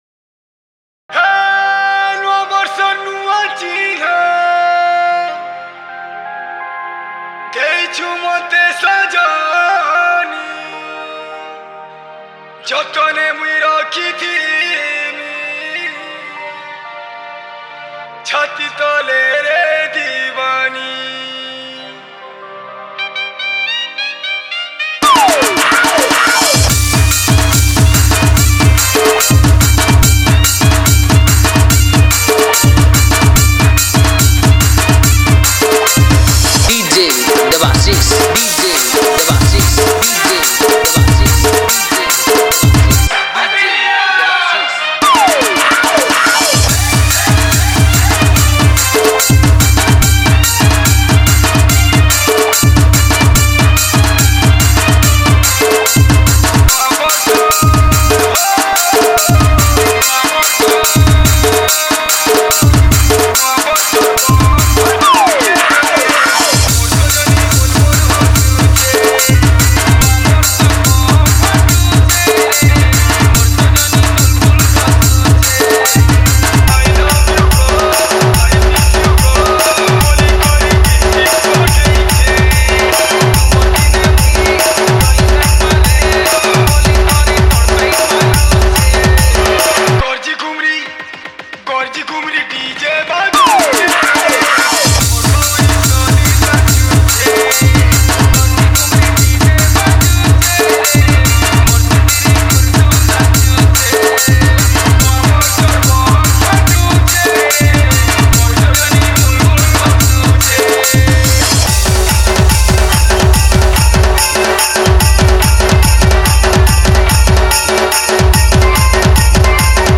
New Year Special Dj Remix